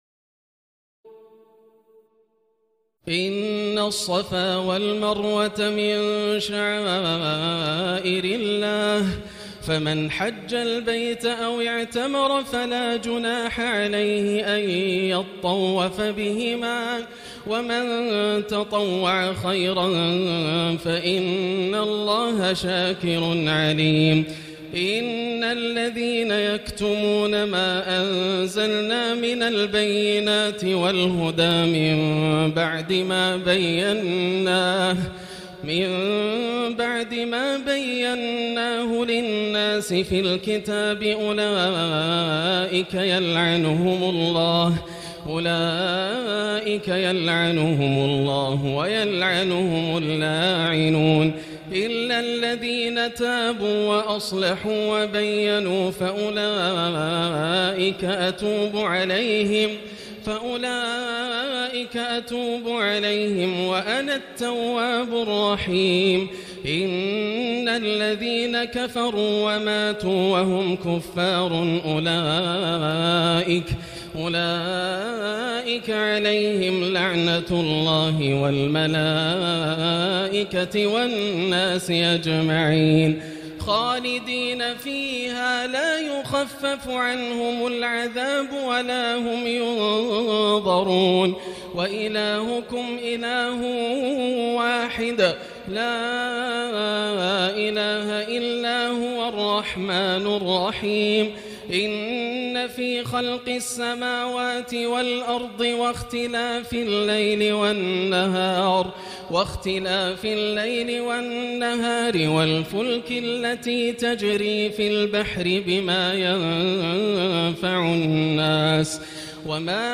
تراويح الليلة الثانية رمضان 1439هـ من سورة البقرة (158-225) Taraweeh 2 st night Ramadan 1439H from Surah Al-Baqara > تراويح الحرم المكي عام 1439 🕋 > التراويح - تلاوات الحرمين